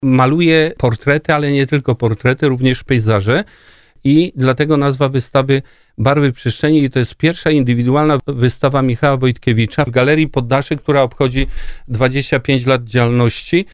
Wydarzenie zapowiadał na antenie RDN Małopolska